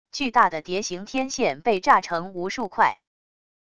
巨大的碟形天线被炸成无数块wav音频